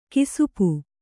♪ kisupu